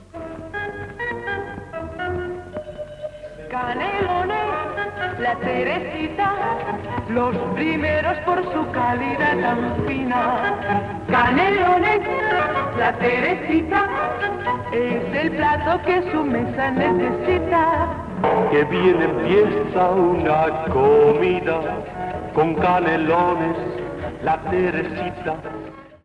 Publicitat de Canalones La Teresita
Extret del programa dedicat al cinquentenari de Radio Panadés emès l'any 1982.